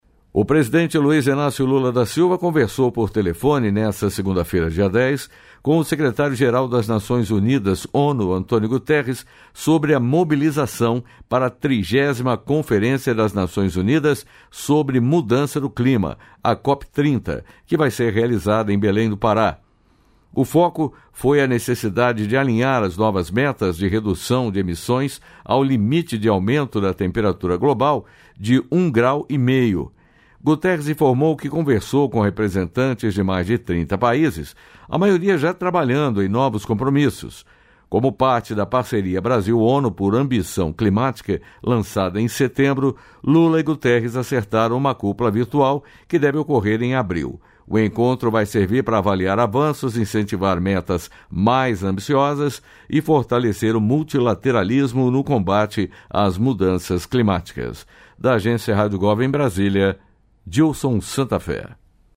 É Notícia